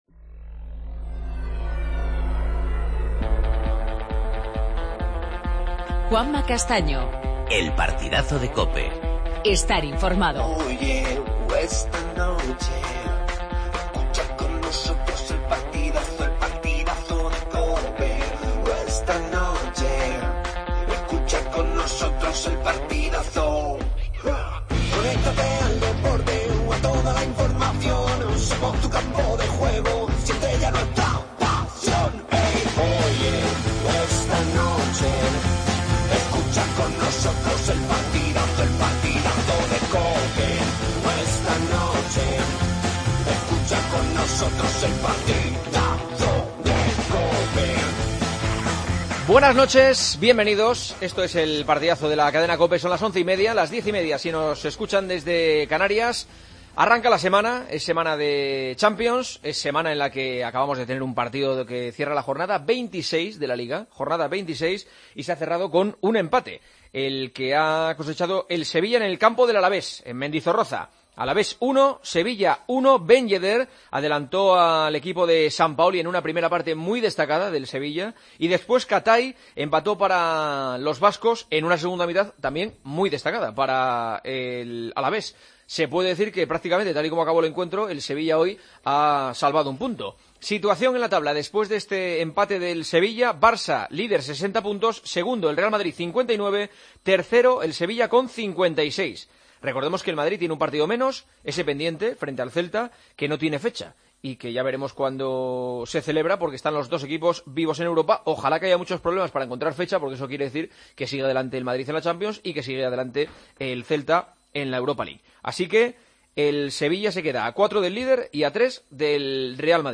AUDIO: Titulares del día. Escuchamos a Iborra, jugador del Sevilla, tras el empate ante el Alavés.